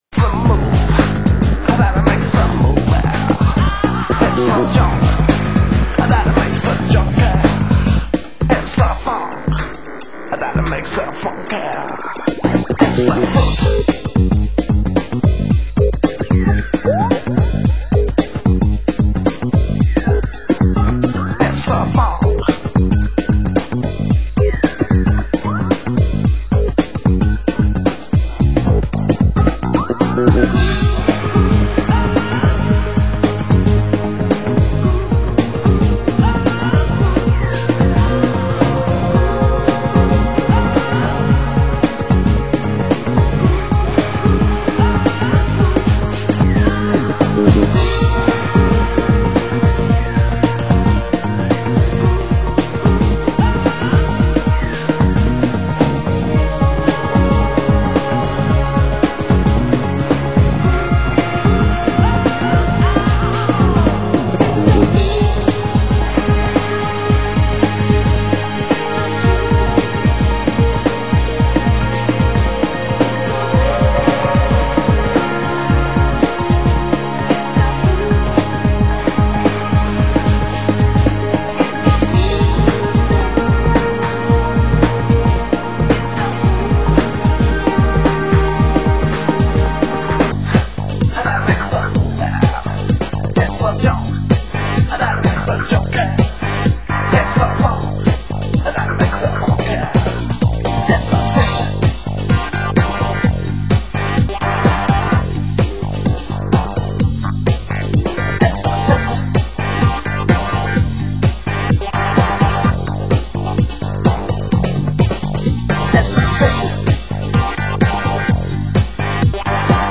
Break Beat